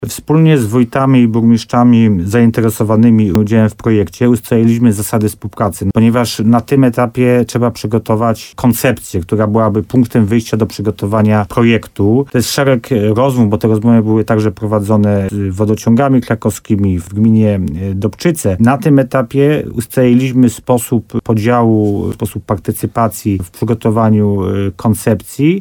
Wójt gminy Tymbark Paweł Ptaszek potwierdził w programie Słowo za słowo na antenie RDN Nowy Sącz, że drugie już spotkanie w tej sprawie miało miejsce w Mszanie Dolnej.